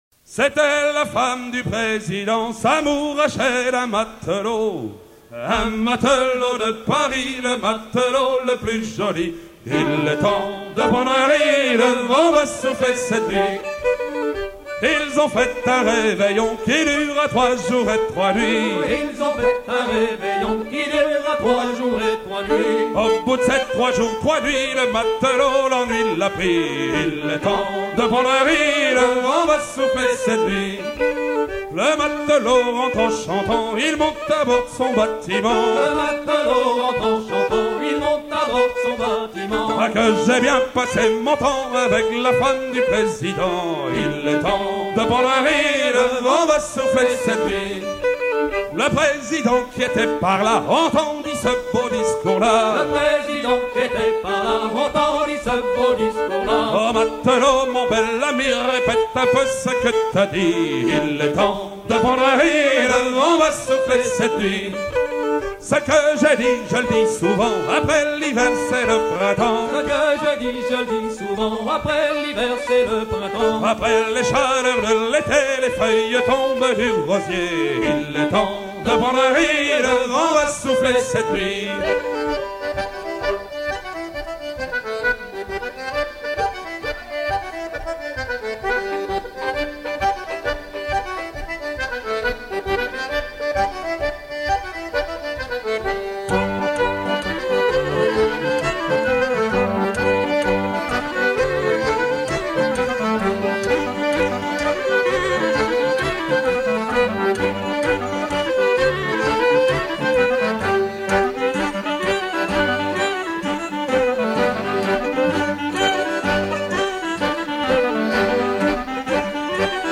version recueillie à Saint-Malo
danse : branle : avant-deux
Genre strophique